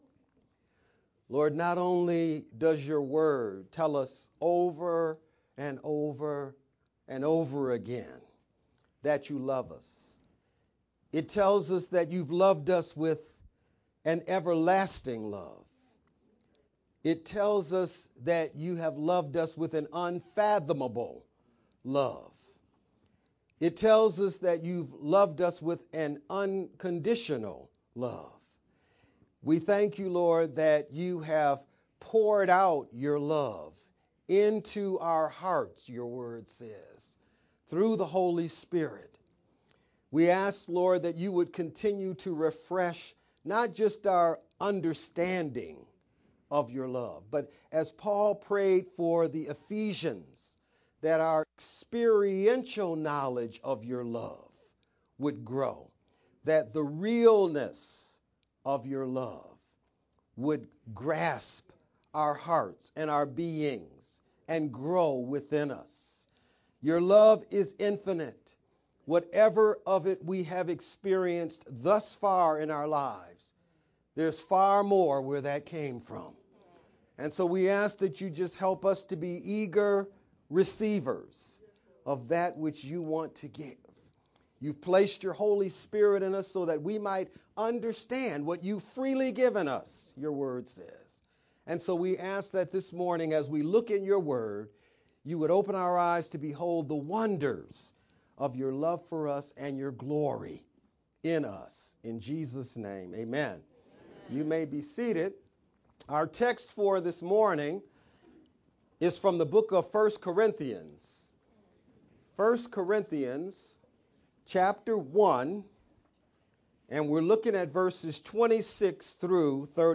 Palm-Sunday-Mar-24th-sermon-only_Converted-CD.mp3